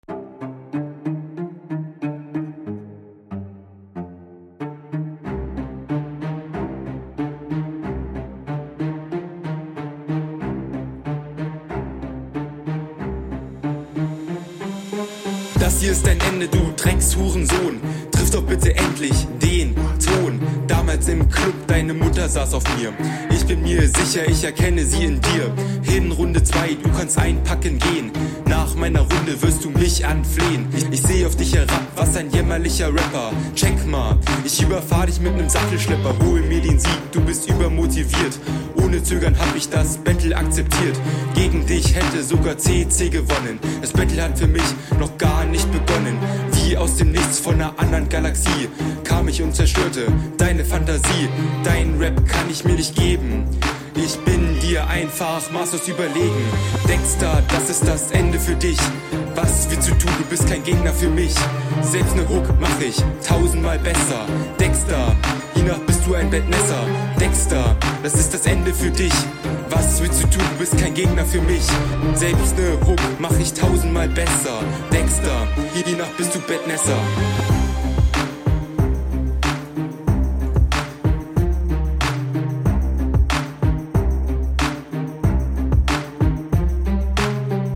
Ja dunkler Beat und ganz anders als das melodische Ding aus Runde 1.